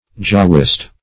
jahwist - definition of jahwist - synonyms, pronunciation, spelling from Free Dictionary
Jahwist \Jah"wist\